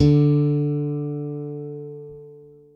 ALEM FING D3.wav